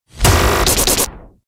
Stutter Lazer Sound Effect Download: Instant Soundboard Button